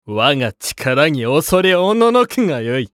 男性
厨二病ボイス～戦闘ボイス～